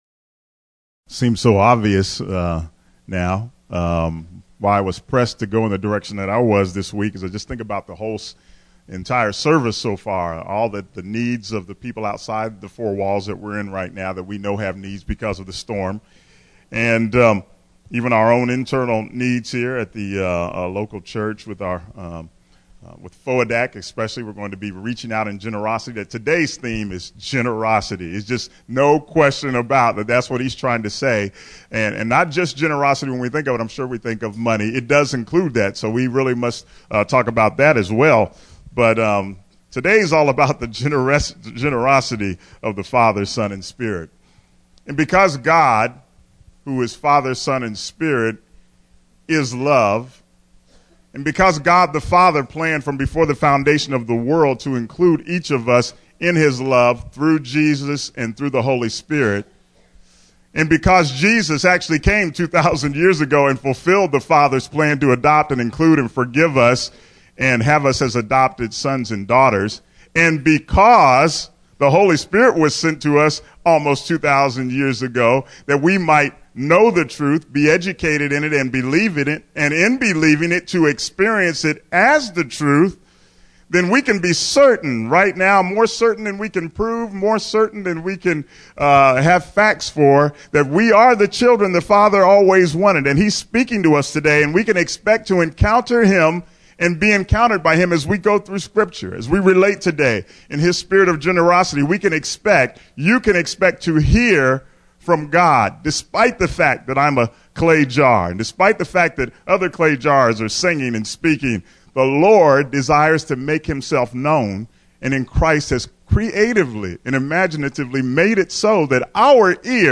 Sermon: Excel Also in the Grace of Giving | Trinity and Humanity